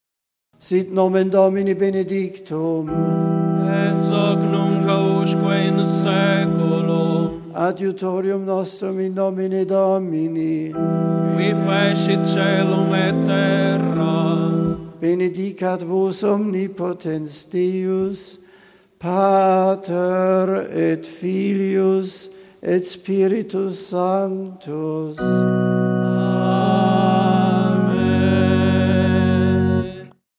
The general audience of Dec. 1 was held in the Vatican’s Paul VI Audience Hall.
Pope Benedict then delivered a discourse in English.